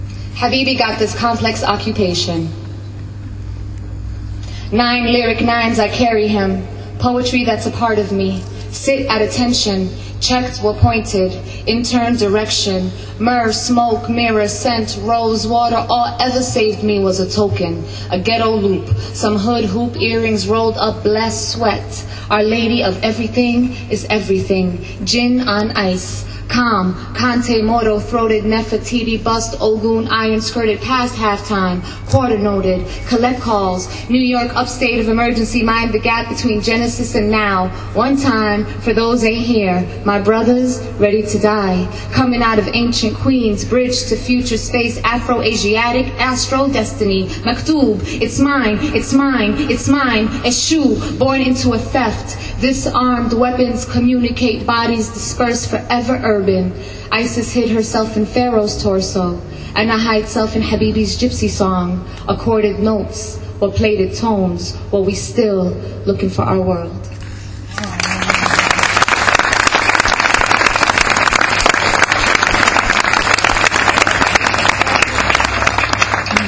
pal_logoPalestinian-American artist Suheir Hammad, previously featured, is currently appearing at the second Palestine Festival of Literature, which as you may recall the zionist entity has tried to disrupt and shut down.
Here are four of Suheir’s poetry readings at the Festival, the first three in English and the fourth short poem mostly in Arabic, as well as a video clip.